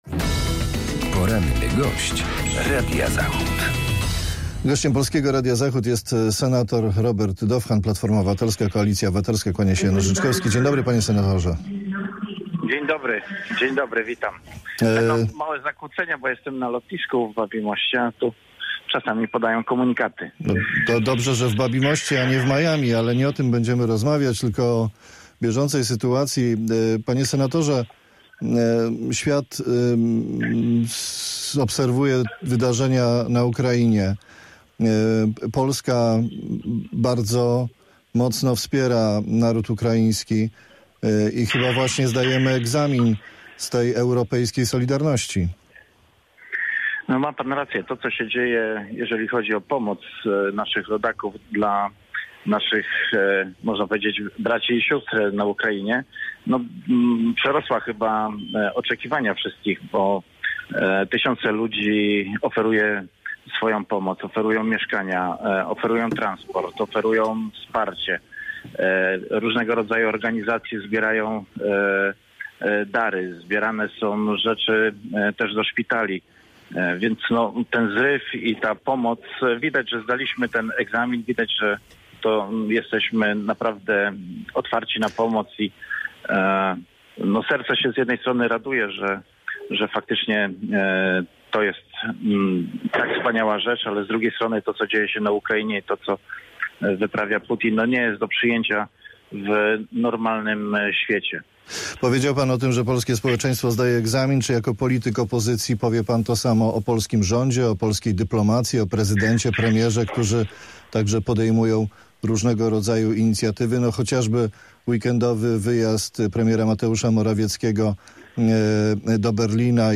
Z senatorem PO rozmawia